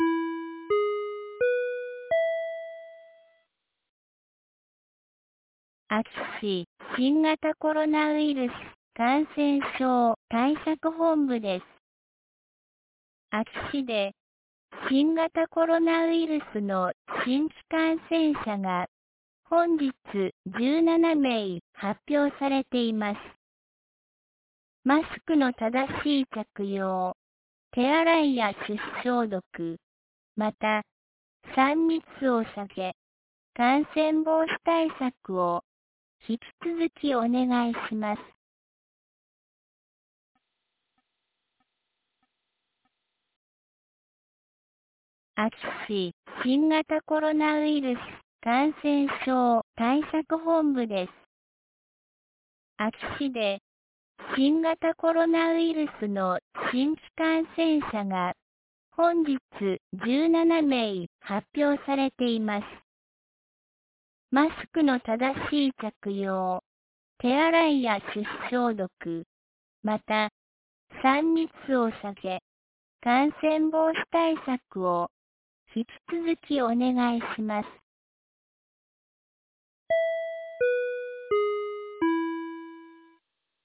2022年08月14日 17時09分に、安芸市より全地区へ放送がありました。